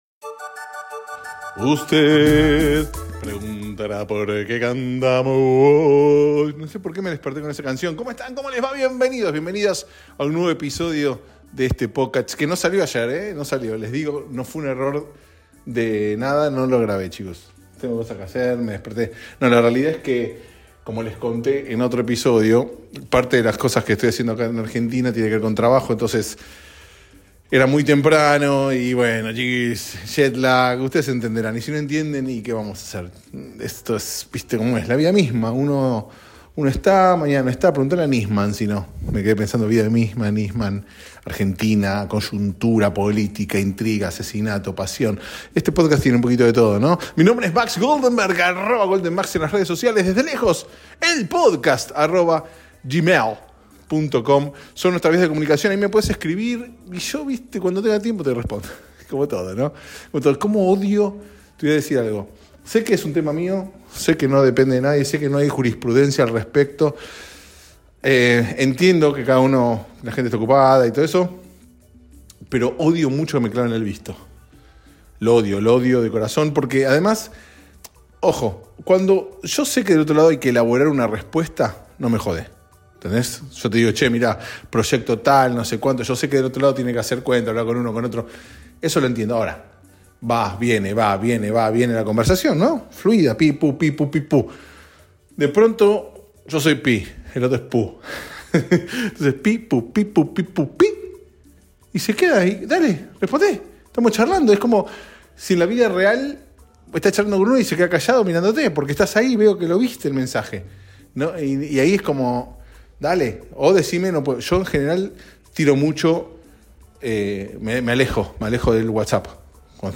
Llegó el viernes y, créase o no, estamos vivos. Un episodio grabado casi literalmente desde el bidet.